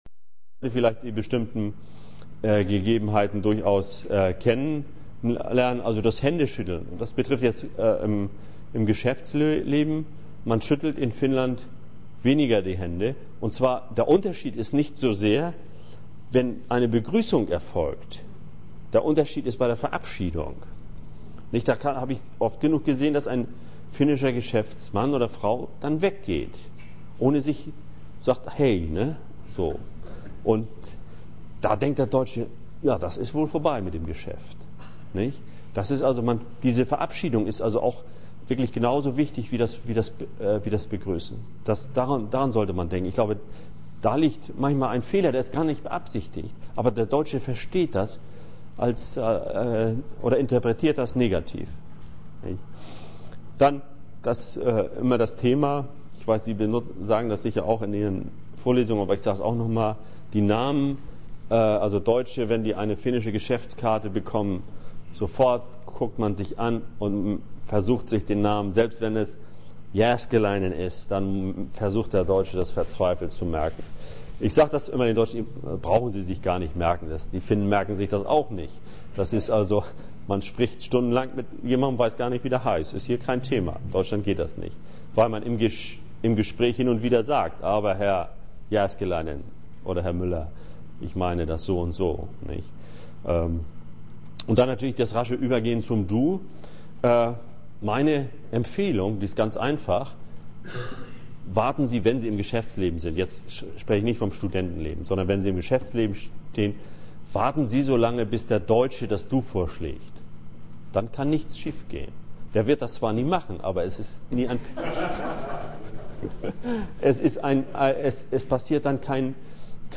Vorlesung